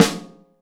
Index of /kb6/Akai_MPC500/1. Kits/Amb Rm Kit